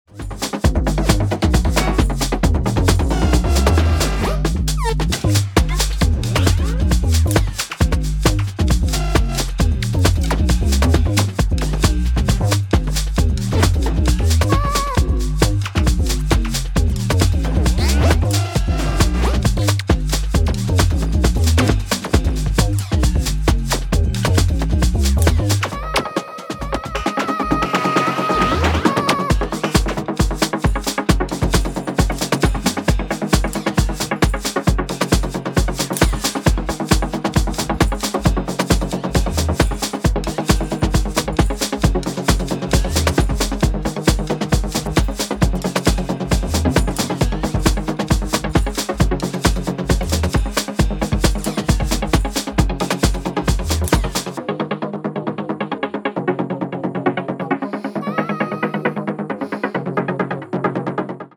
浮遊感や中毒性、温かみを備えたナイスな一枚に仕上がっています。